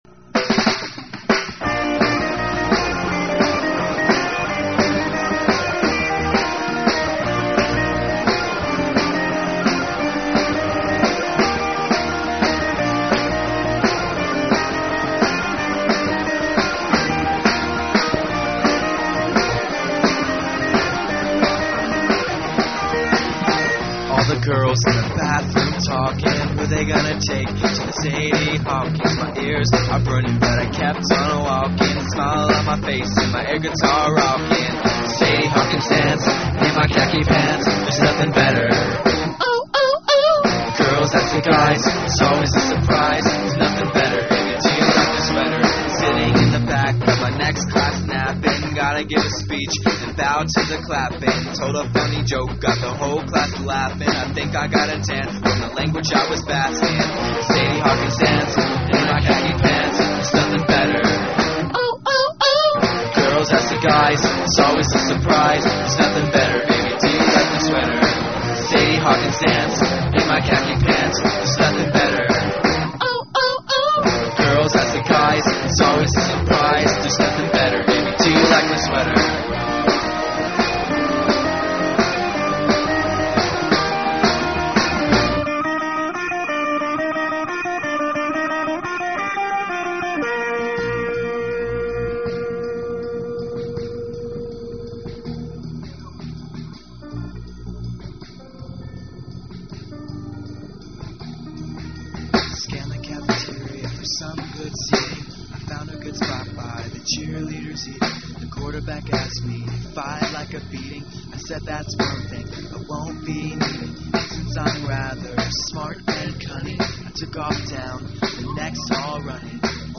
Vocals
Lead Guitar
Drums/Back-up Vocals
Bass/Back-up Vocals
Keyboards